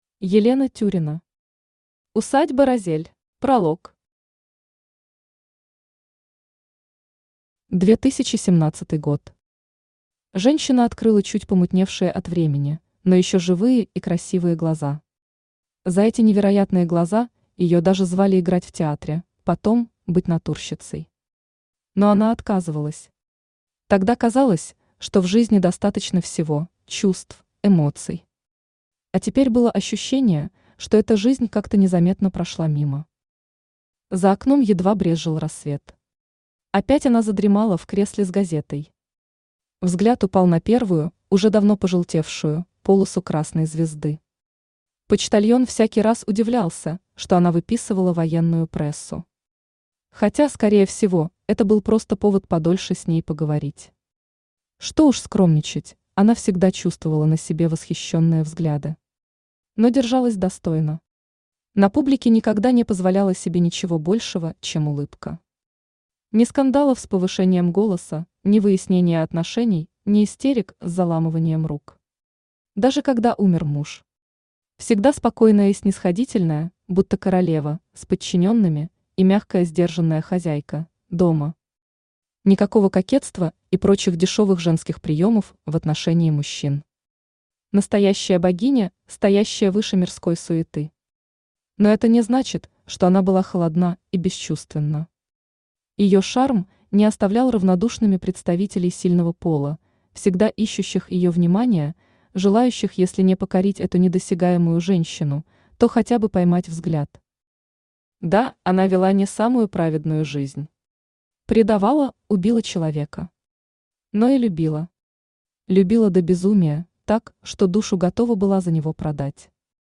Aудиокнига Усадьба «Розель» Автор Елена Андреевна Тюрина Читает аудиокнигу Авточтец ЛитРес.